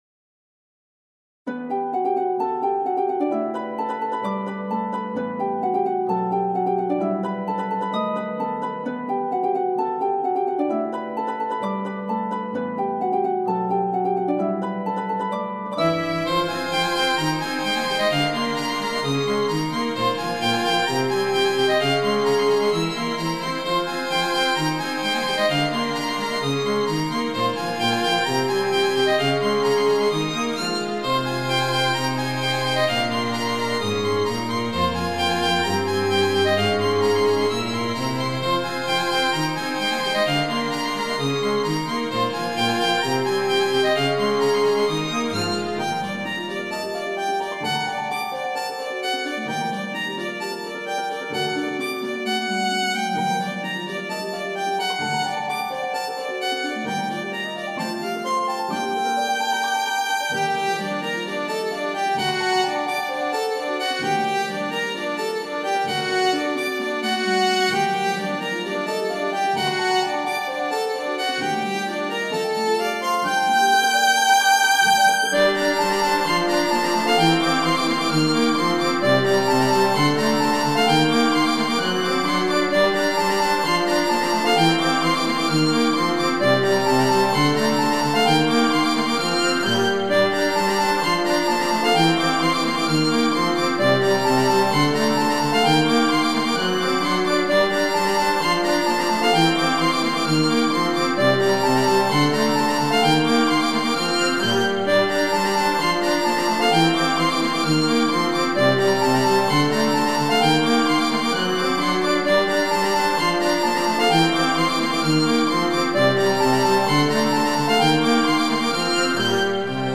ハープ+弦楽4部の弦楽合奏。
今回はサビの部分にヴァイオリンソロ＋ピッツィカートを入れたりして変化を持たせてます。
この曲はいじり方によって、メルヘンさを十二分にかもし出す曲ですね。